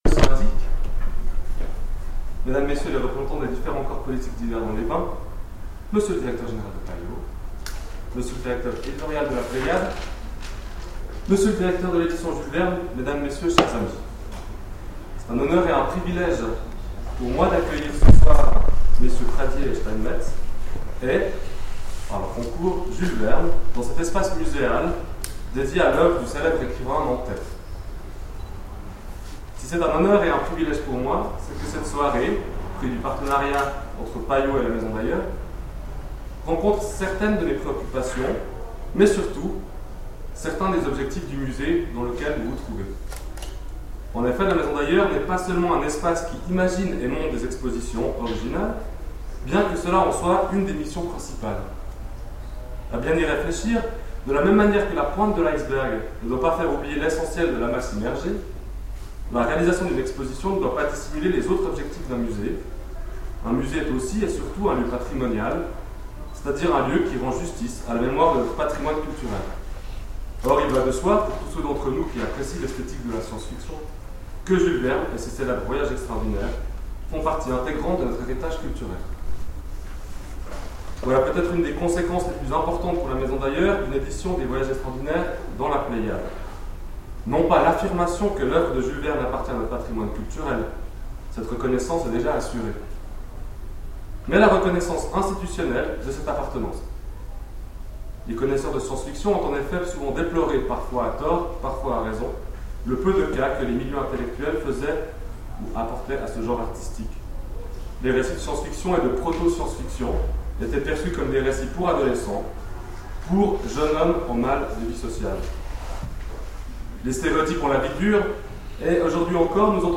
Conférence Jules Verne : Voyages extraordinaires en Pléiade